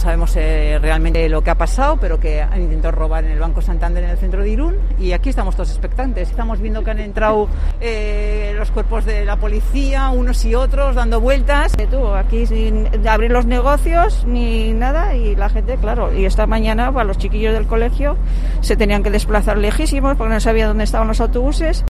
Vecinas de Irun sobre el intento de robo en un banco